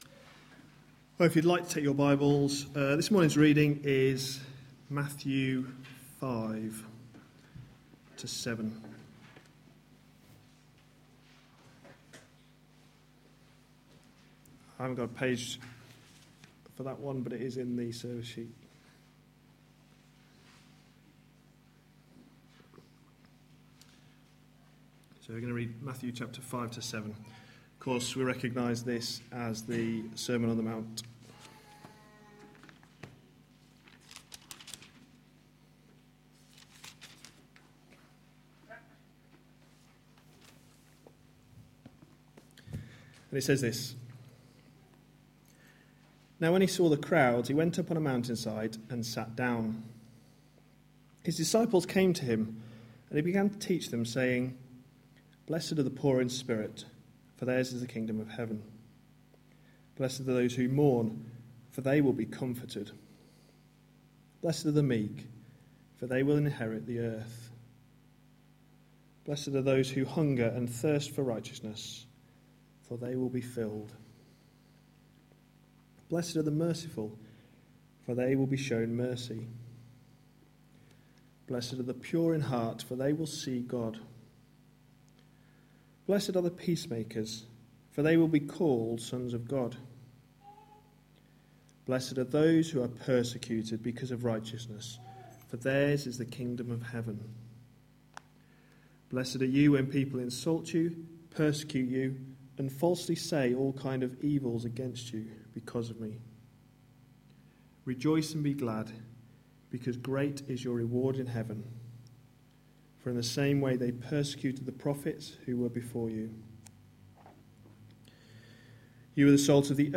A sermon preached on 11th May, 2014, as part of our Changing the way you think series.